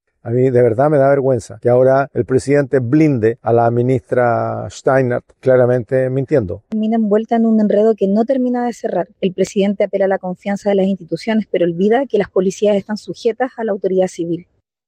En ese sentido, el senador Iván Flores (DC) acusó derechamente que desde el Gobierno están “institucionalizando la mentira”, mientras que la diputada Tatiana Urrutia (FA) recordó que la PDI se encuentra subordinada al poder civil.